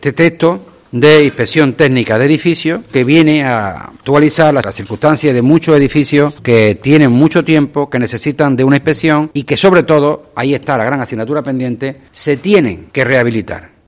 Escucha al concejal de Hacienda y Urbanismo sobre la Ordenanza de Inspección Técnica de Edificios